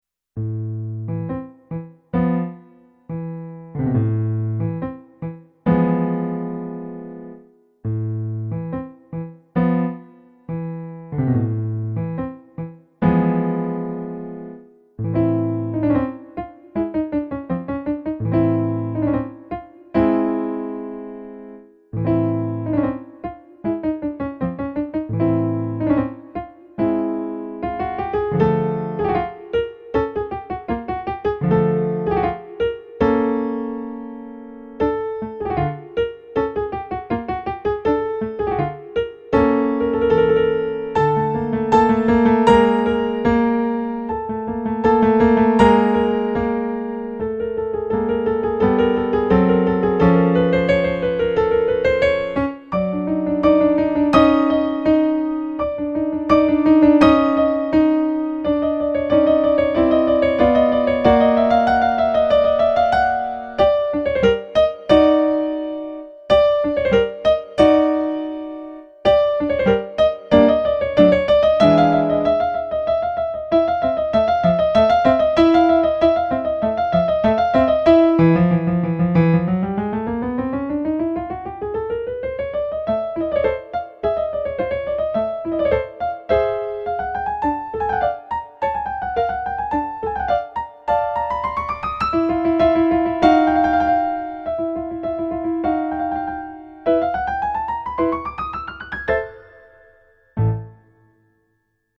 eight piano solo arrangements.  34 pages.
spy remix